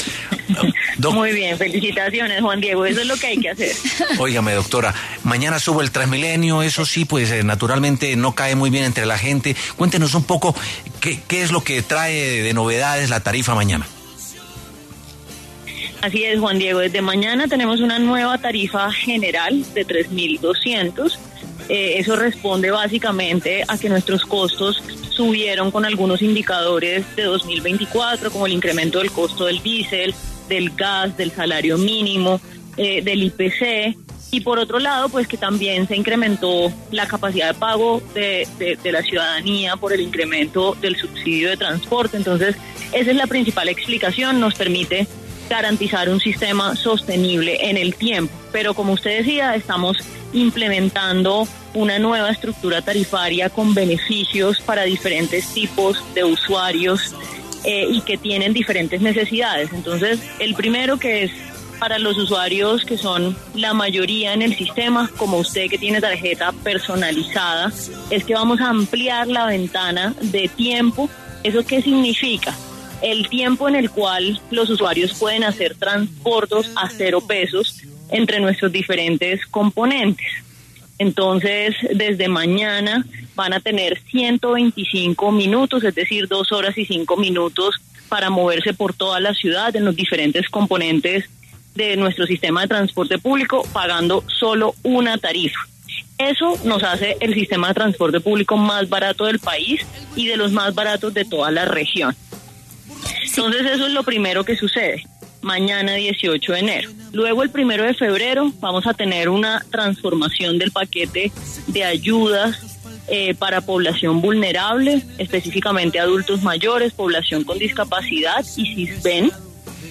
María Fernanda Ortiz, gerente de TransMilenio, habló en W Sin Carreta sobre el aumento a 3.200 en el pasaje del sistema que entra en vigencia este sábado 18 de enero.